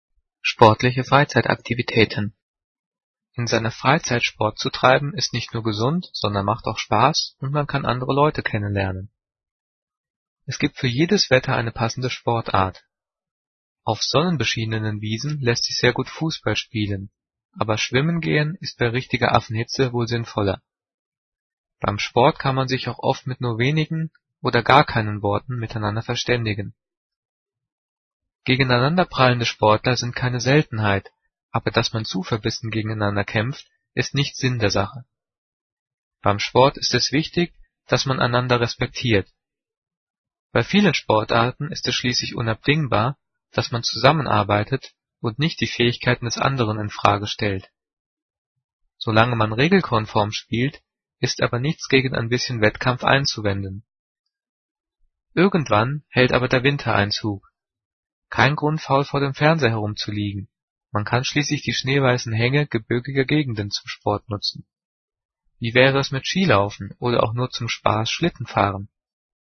Gelesen:
gelesen-sportliche-freizeitaktivitaeten.mp3